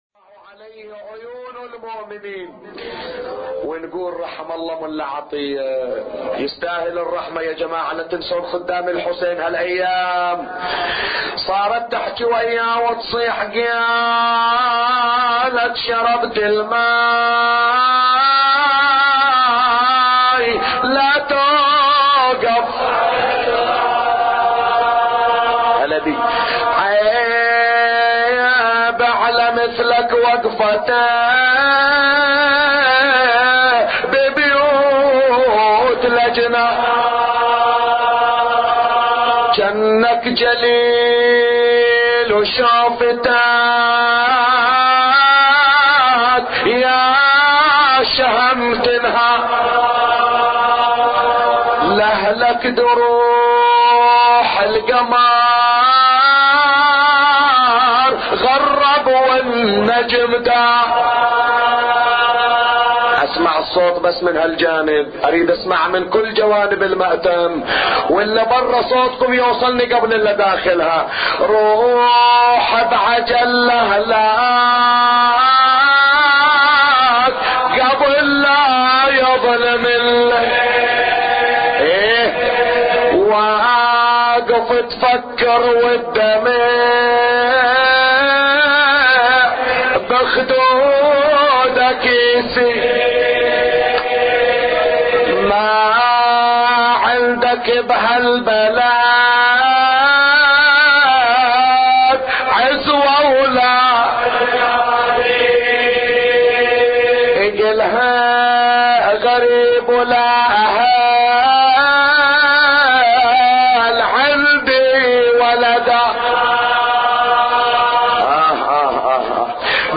أبيات حسينية – ليلة الرابع من شهر محرم